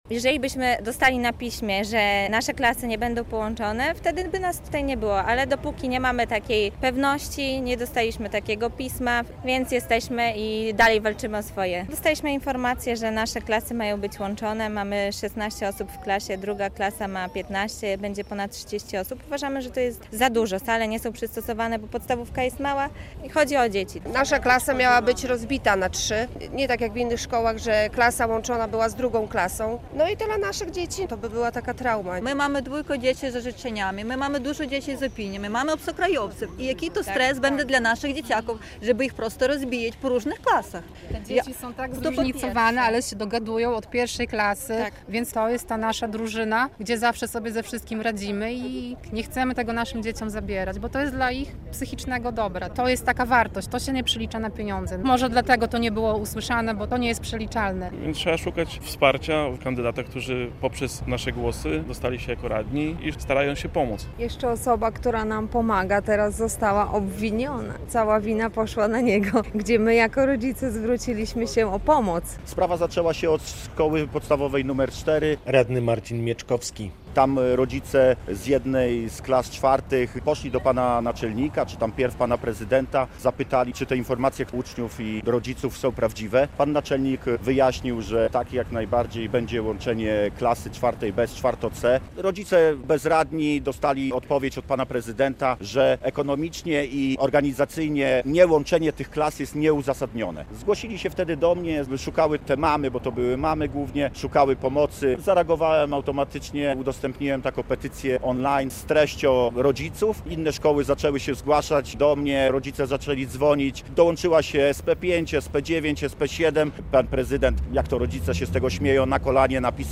Kilkudziesięciu rodziców uczniów łomżyńskich podstawówek przed sesją rady miejskiej zorganizowało w środę (28.05) pikietę na Starym Rynku.